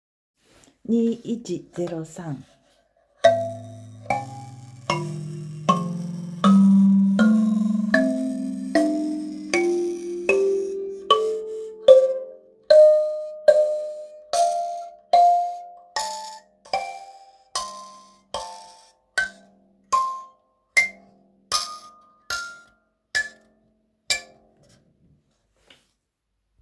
ザウォセのイリンバ中 K　2103 一点物【小売限定】
計算しつくされたバー とボディバランス、ホール部分にクモの卵膜を装着して荘厳な「ビビリ音」を実現。西洋近代楽器では表現しきれない「アフリカ特有の倍音」を再現できるスゴイ楽器です。
ホール部分にはクモの卵膜が装着され独特サウンドが楽しめます。
素材： 木・鉄・皮・クモの卵膜